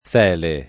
fiele [fL$le] s. m. — antiche forme latineggianti fele [
f$le] e, più rara, felle [